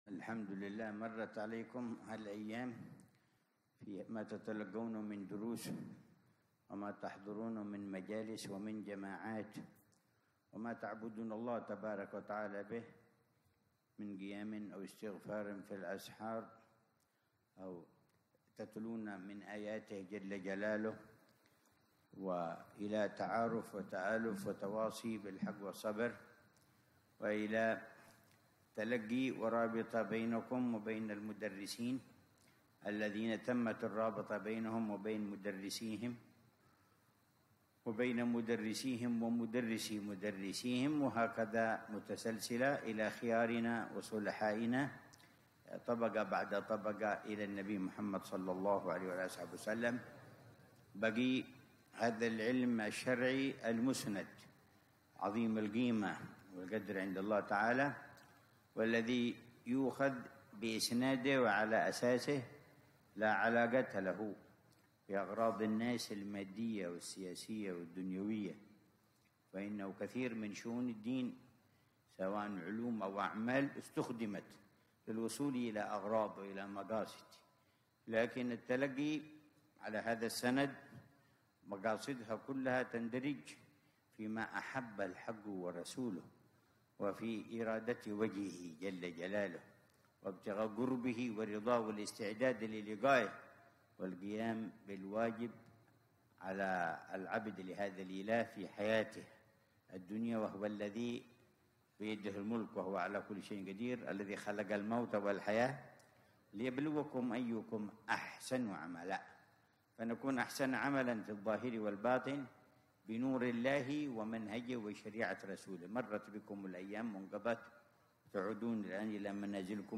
محاضرة في حفل اختتام الدورة الفرعية والإعدادية بدار المصطفى 1446هـ
محاضرة العلامة الحبيب عمر بن حفيظ في حفل اختتام الدورة الفرعية والإعدادية، بدار المصطفى بتريم، عصر يوم الإثنين 6 ذو الحجة 1446هـ